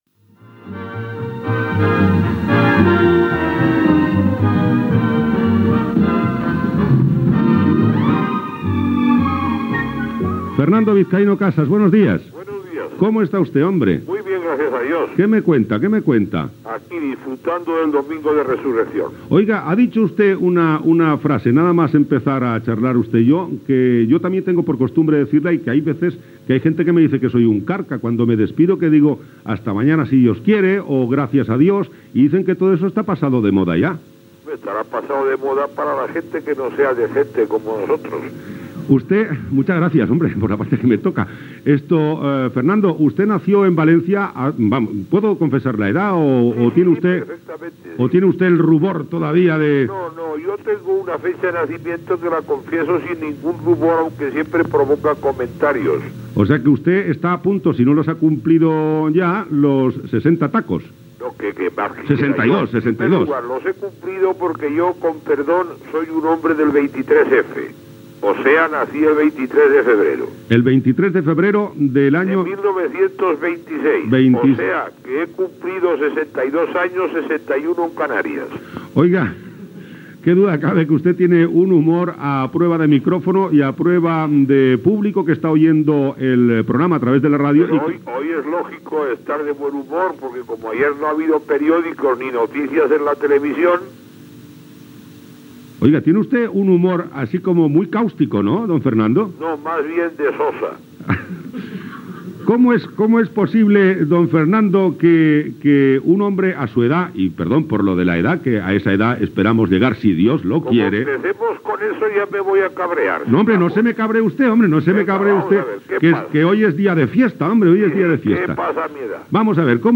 a0ae4c7ed0aae86d05fb98a617d74f62b409d31b.mp3 Títol COPE Miramar Emissora COPE Miramar Cadena COPE Titularitat Privada estatal Descripció Entrevista a Fernando Vizcaino Casas.